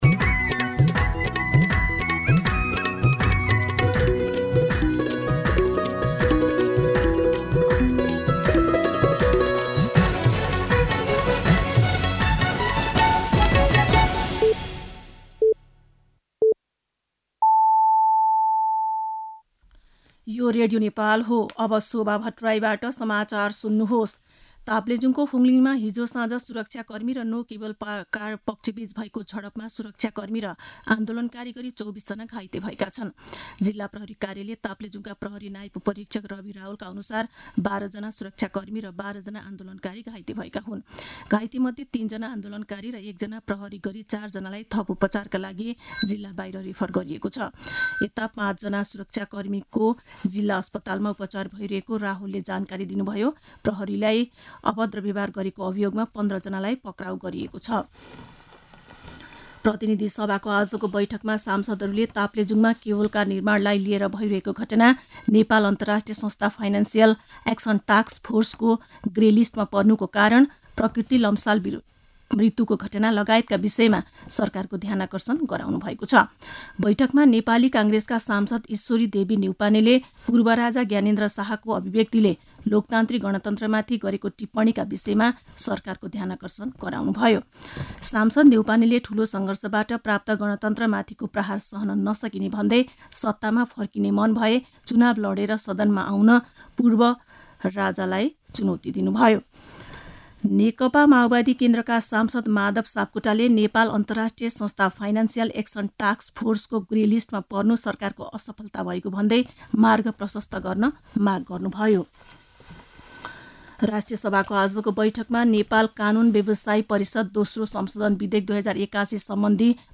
दिउँसो ४ बजेको नेपाली समाचार : १२ फागुन , २०८१
4-pm-Nepali-News-3.mp3